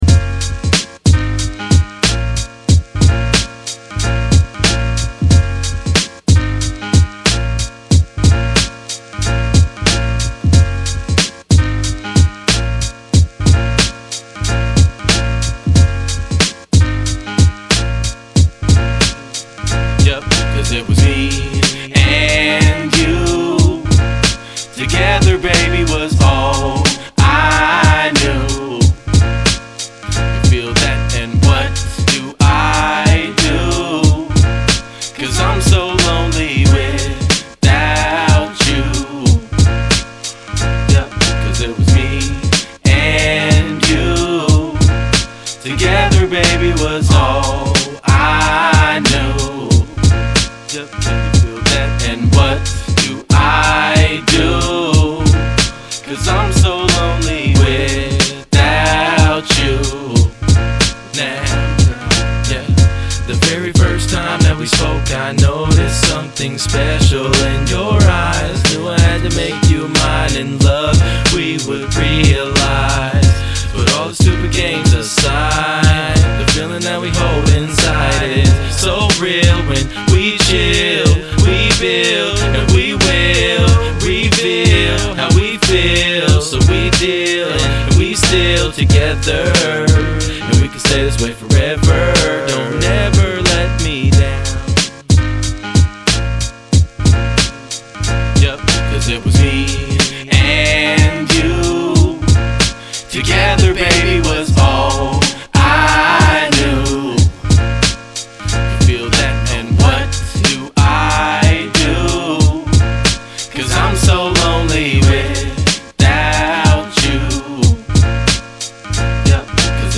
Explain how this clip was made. Recorded at Ground Zero Studios & Seattle ChopShop